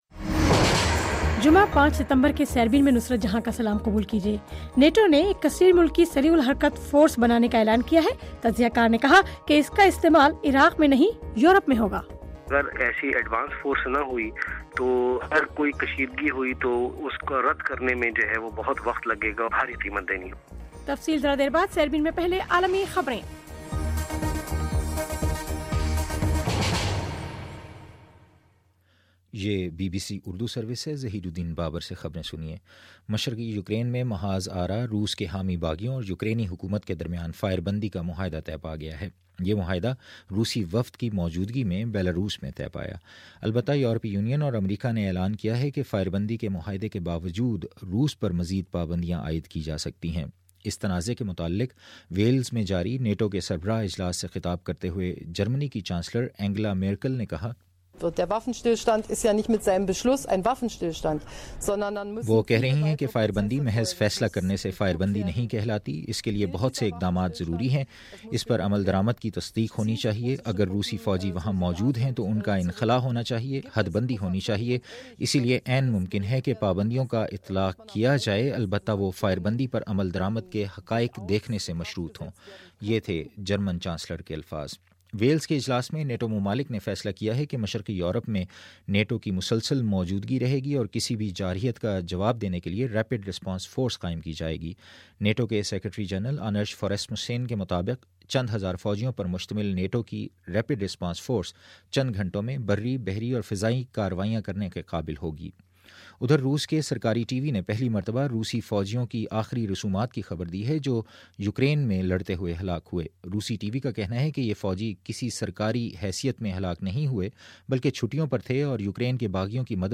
پانچ ستمبر کا سیربین ریڈیو پروگرام